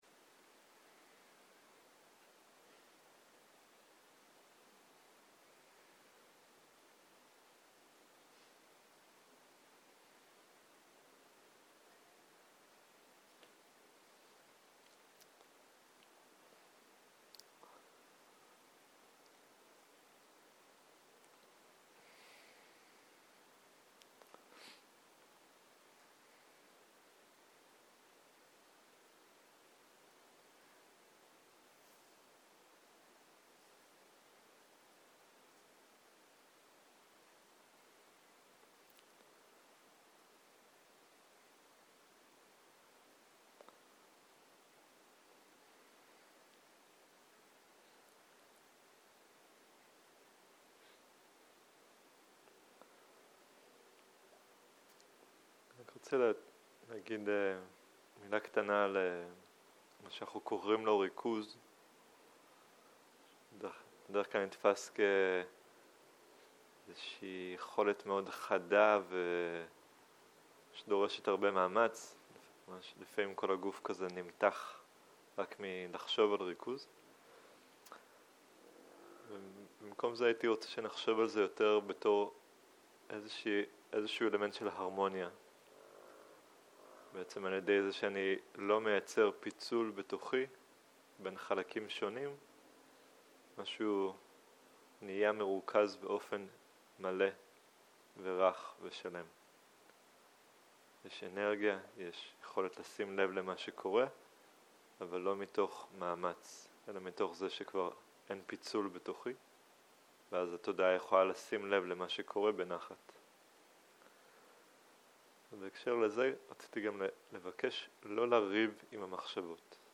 מדיטציה מונחית
Dharma type: Guided meditation שפת ההקלטה